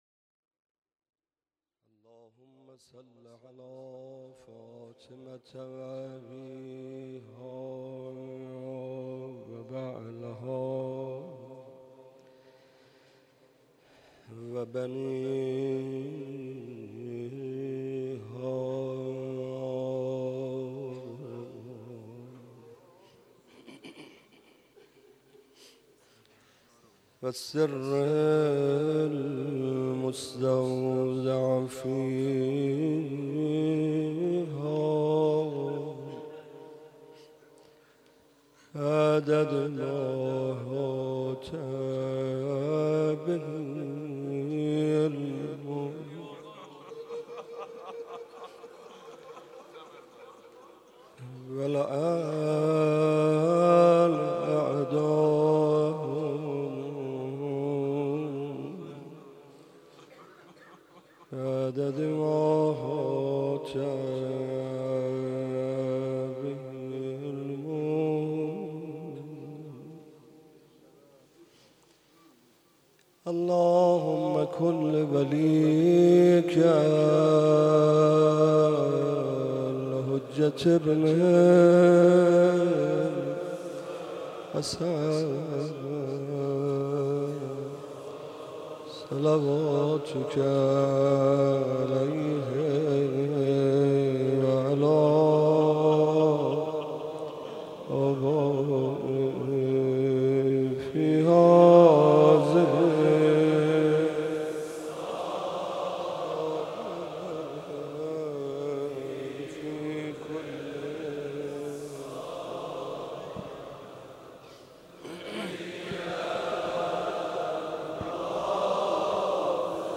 روضه حضرت علی اصغر علیه السلام